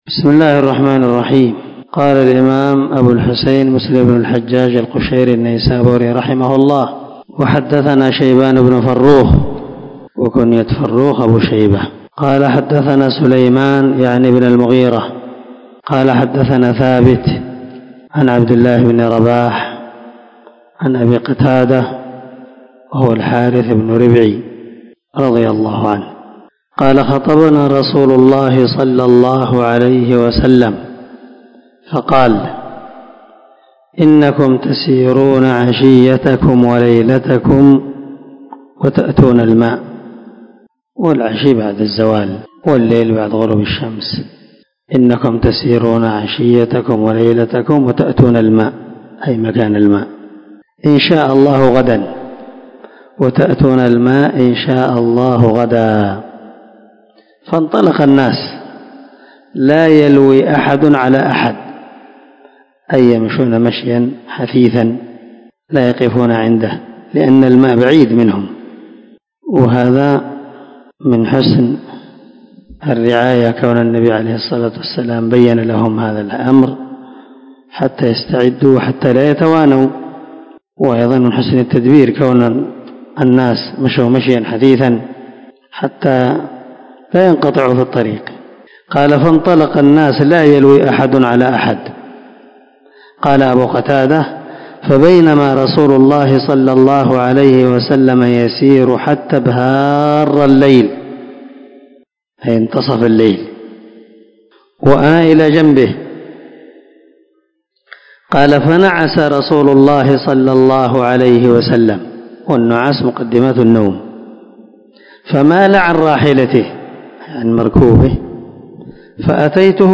431الدرس 103 من شرح كتاب المساجد ومواضع الصلاة حديث رقم ( 681 ) من صحيح مسلم